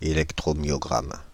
Ääntäminen
France (Île-de-France): IPA: /e.lɛk.tʁɔ.mjɔ.ɡʁam/